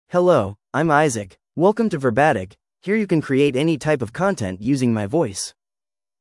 MaleEnglish (United States)
Isaac is a male AI voice for English (United States).
Voice sample
Listen to Isaac's male English voice.
Isaac delivers clear pronunciation with authentic United States English intonation, making your content sound professionally produced.